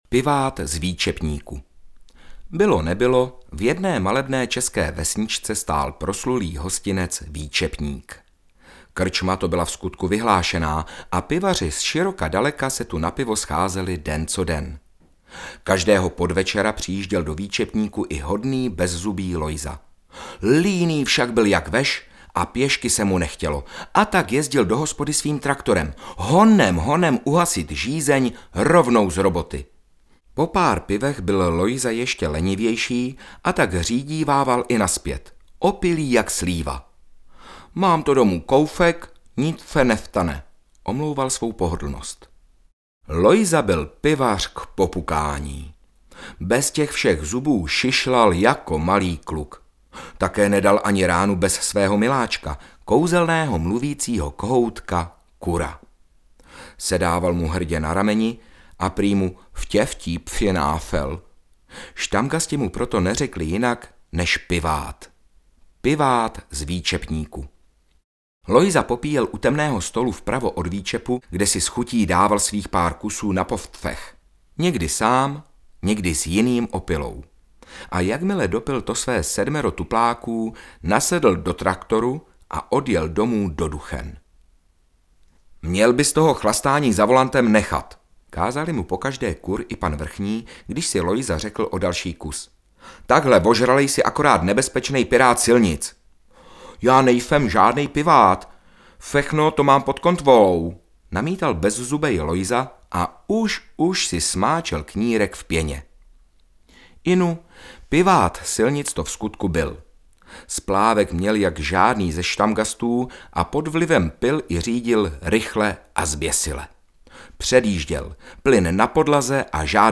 České pivní pohádky audiokniha
Ukázka z knihy